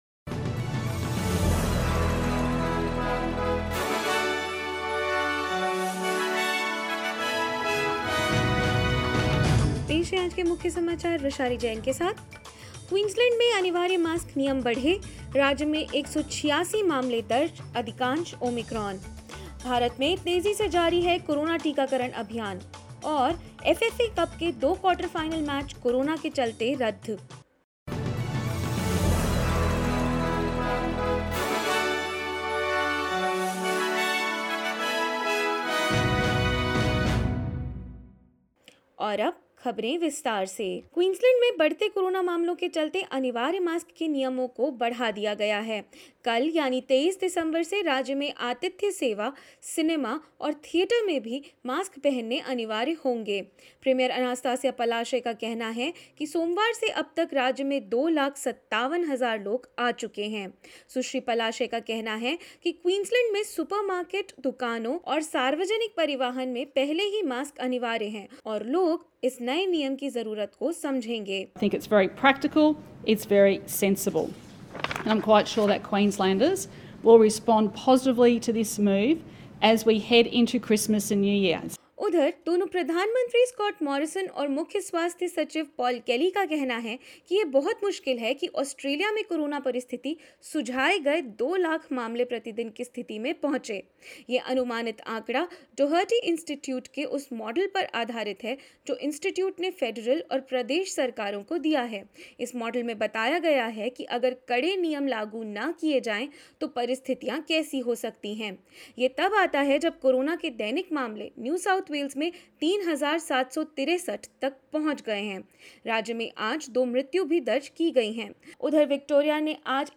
In this latest SBS Hindi news bulletin of Australia and India: Queensland extends mask mandates to hospitality staff, theatres and cinemas as Omicron becomes dominant strain in the state; PM Scott Morrison urges the nation to wear masks and more.